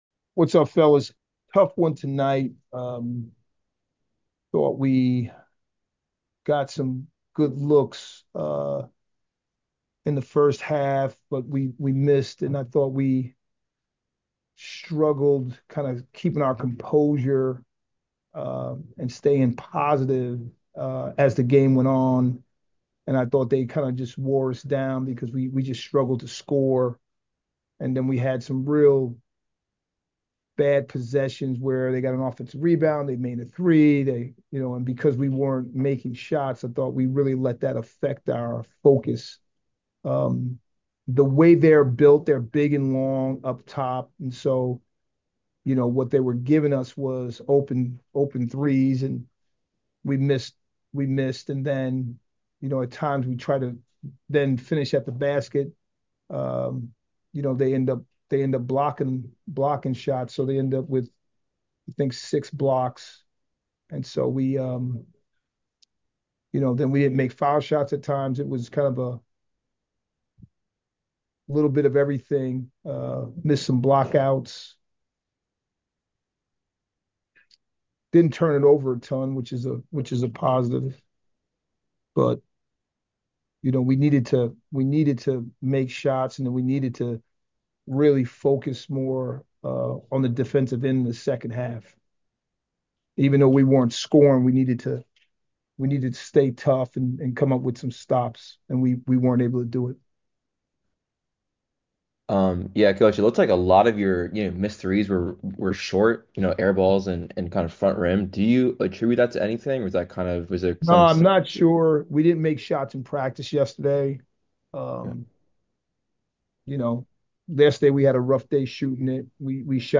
San Diego Postgame Interview